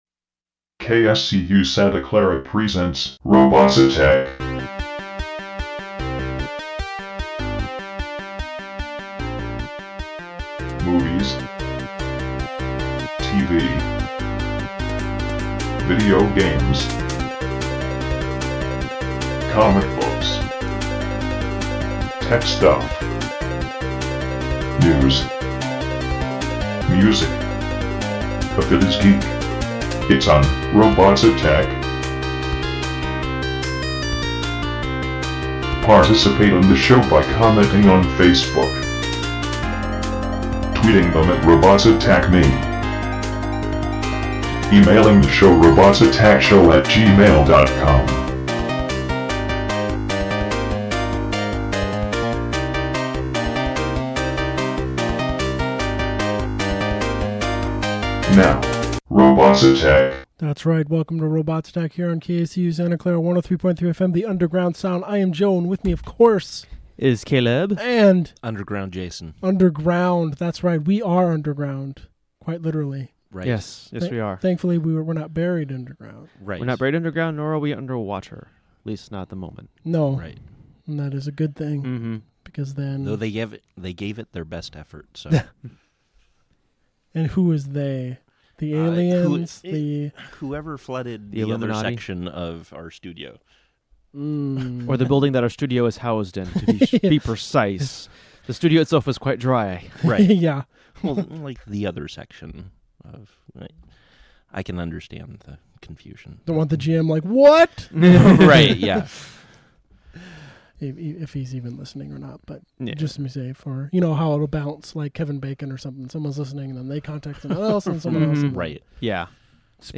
Listen live Mondays from 7pm to 9pm on 103.3 FM KSCU Santa Clara